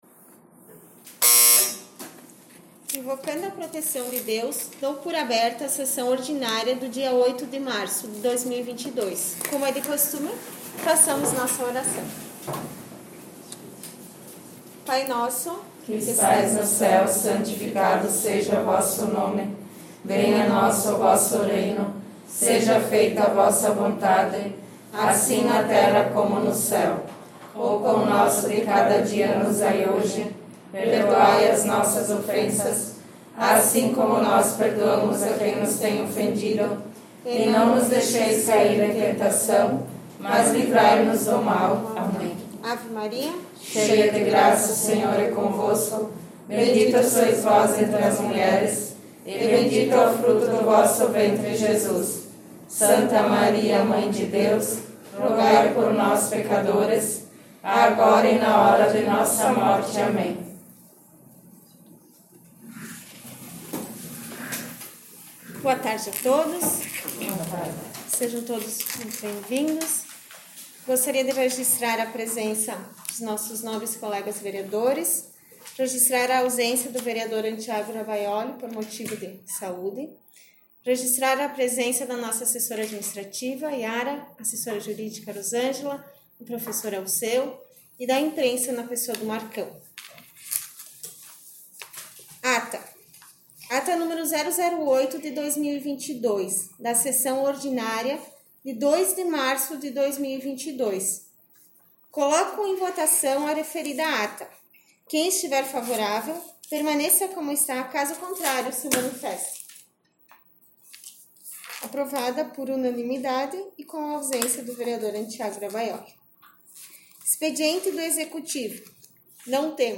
6 - Sessão Ordinária 8 de mar 18.01.mp3